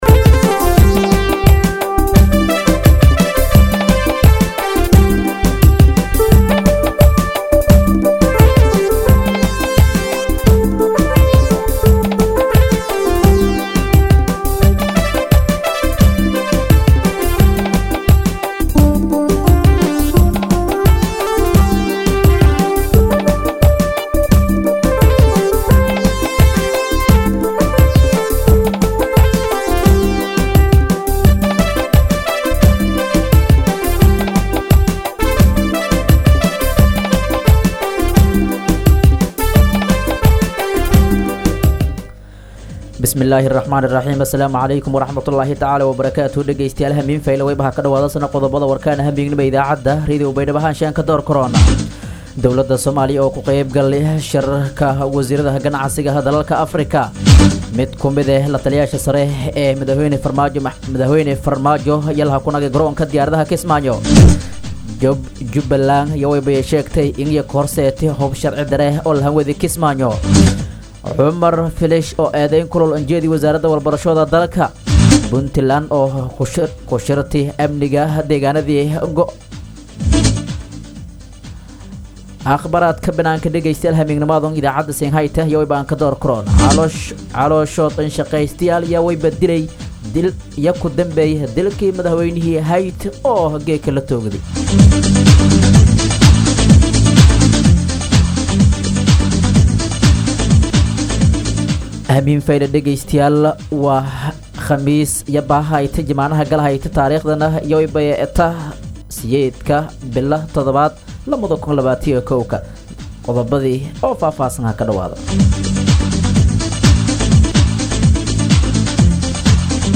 DHAGEYSO:- Warka Habeenimo Radio Baidoa 8-7-2021